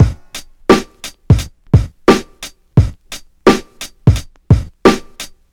• 87 Bpm 90s Rap Drum Loop Sample G# Key.wav
Free drum beat - kick tuned to the G# note. Loudest frequency: 779Hz
87-bpm-90s-rap-drum-loop-sample-g-sharp-key-b1f.wav